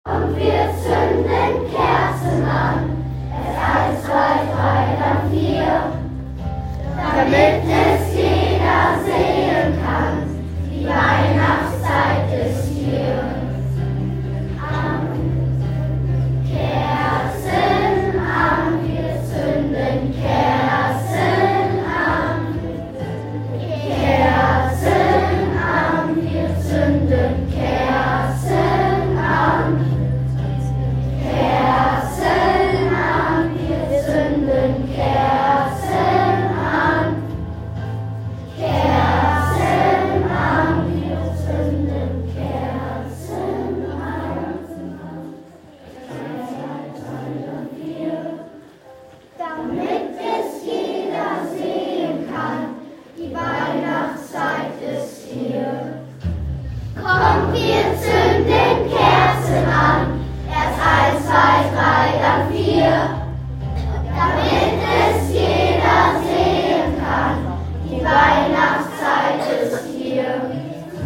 Adventssingen
Jeden Montag in der Adventszeit haben wir uns in der Aula getroffen, gemeinsam unseren schönen Weihnachstbaum bewundert und viele Lieder gehört und gemeinsam gesungen.